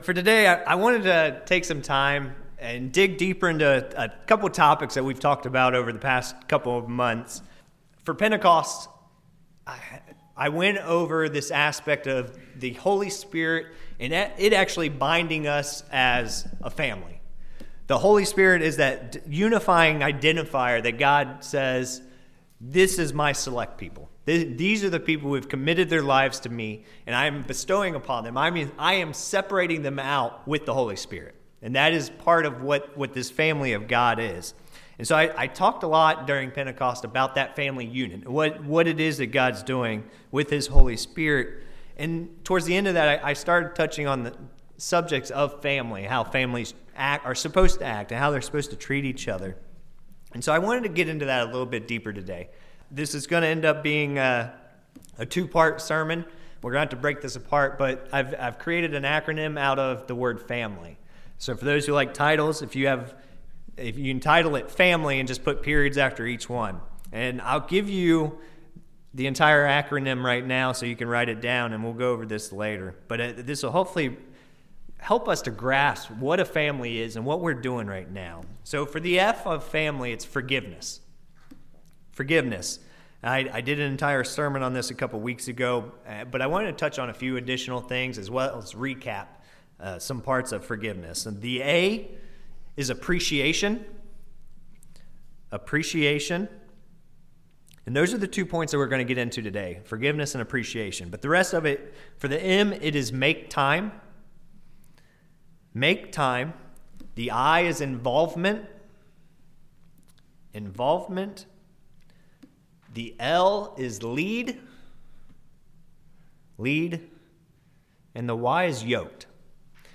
This first sermon covers part of the acronym for the word "Family" to give us building blocks for developing a stronger physical as well as spiritual family unit.
Given in Ft. Wayne, IN